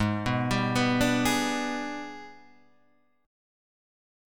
G#mM7b5 chord {4 2 5 4 3 4} chord